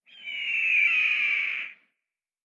tt_s_ara_cfg_eagleCry.ogg